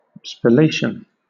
Ääntäminen
UK : IPA : /spəˈleɪʃ(ə)n/